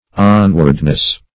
Onwardness \On"ward*ness\, n. Progress; advancement.